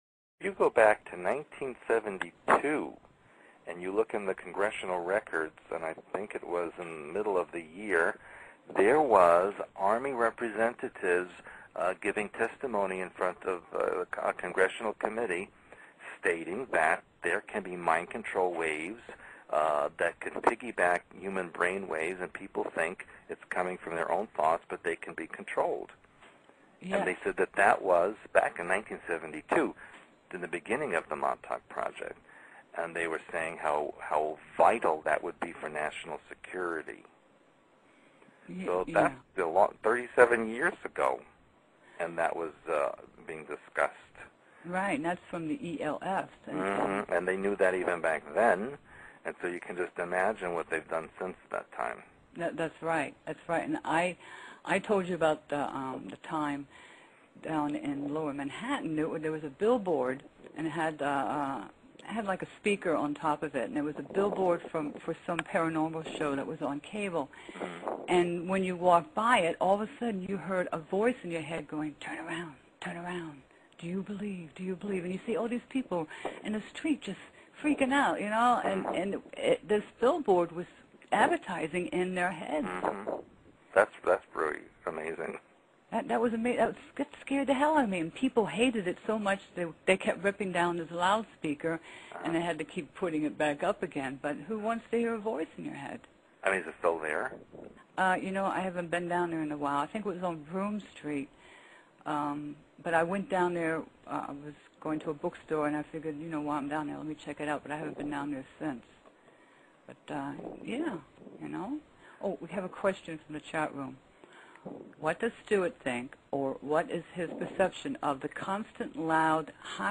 Extremely Low Frequency (ELF) Waves from GWEN/Cell Towers for Torture, Brainwashing and Mind Control.. 2mins in, tinnitus/ringing in the ears is caused by ELF.